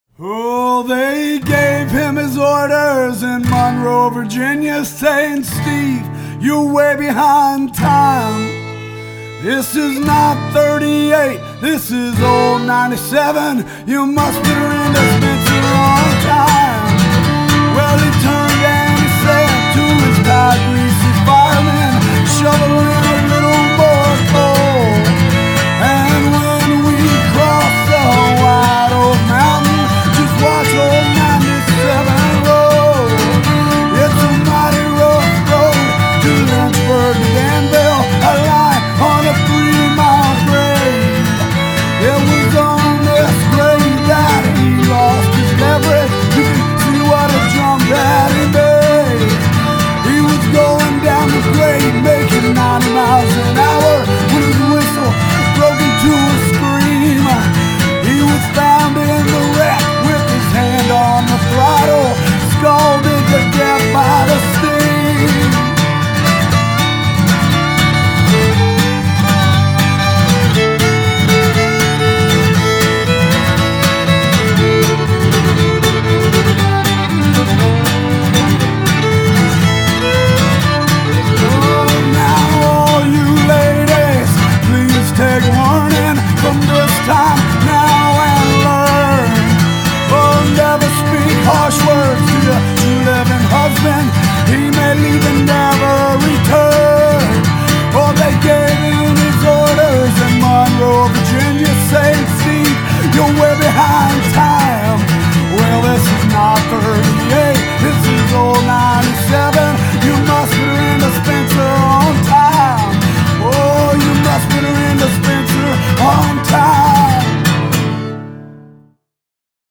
Punk and Folk